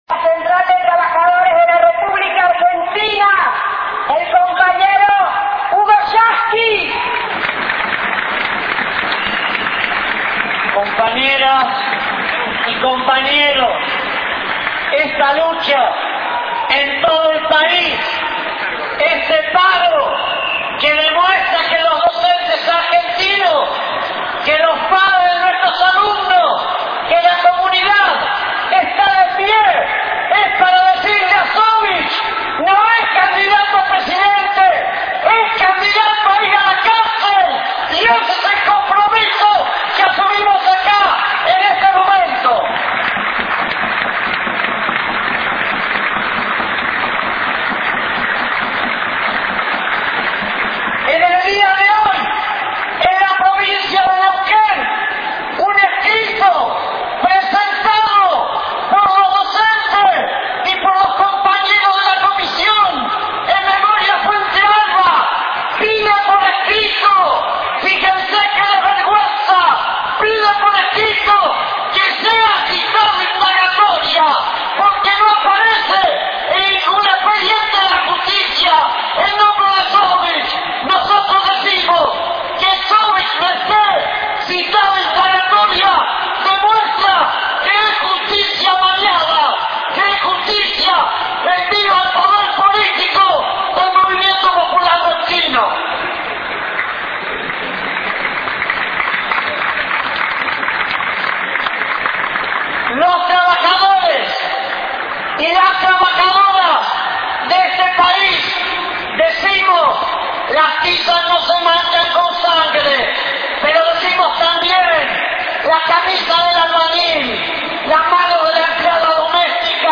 - 4/10/07 - Audio del discurso de Hugo Yasky de la marcha por Carlos Fuentealba
El secretario general de la CTA, Hugo Yasky, encabezó la movilización.
Audio de Discurso de Hugo Yasky